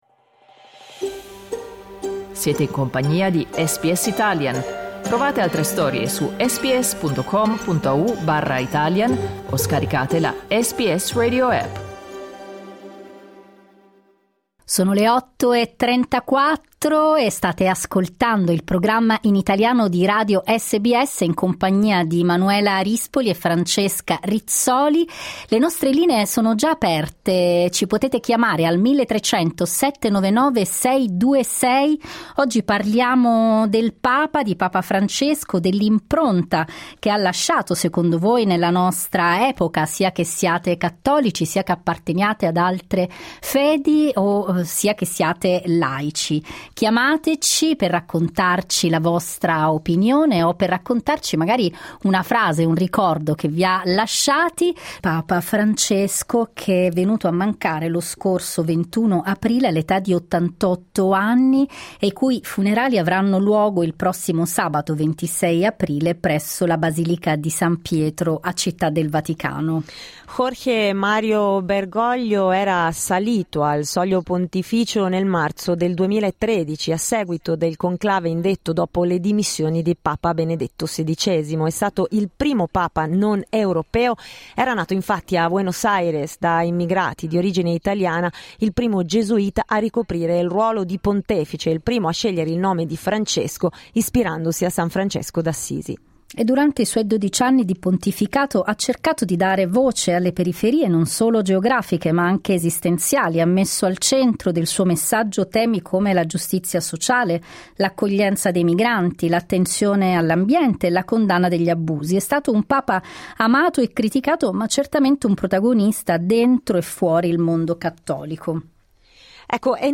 Questo è quello che abbiamo chiesto in diretta ad ascoltrici e ascoltatori durante il programma di oggi.